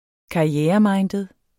karriereminded adjektiv Bøjning -, -e eller (uofficielt) - Udtale [ -ˌmɑjndəð ] Betydninger som lægger stor vægt på at gøre karriere; som prioriterer karrieren højere end privatlivet (familieliv, samvær med venner osv.)